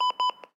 Звук дефибриллятора писк